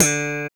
Index of /90_sSampleCDs/Roland L-CD701/BS _Funk Bass/BS _5str v_s